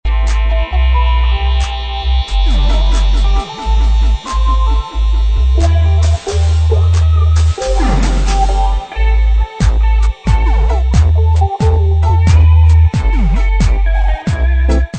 électro dub